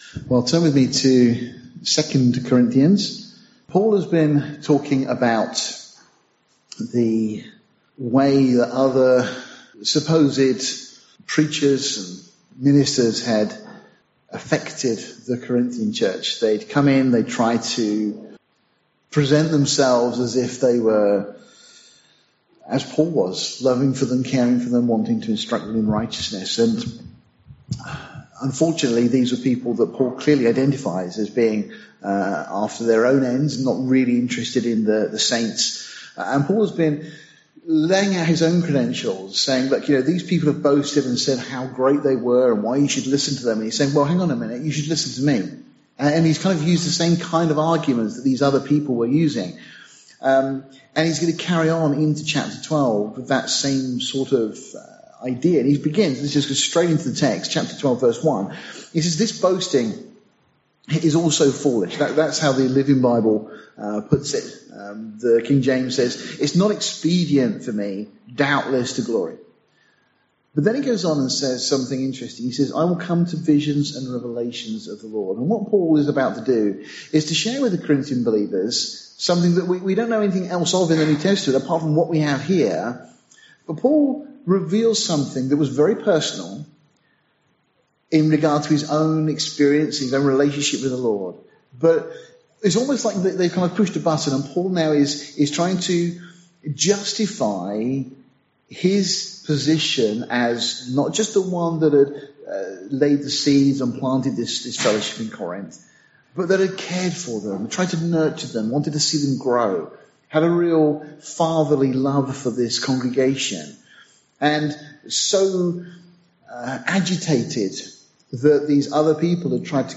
May you be blessed and encouraged by this teaching.